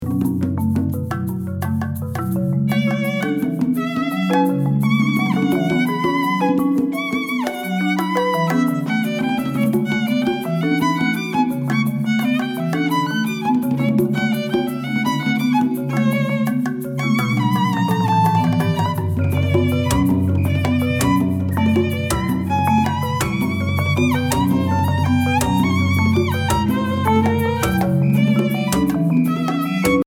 chamber music for marimba and other instruments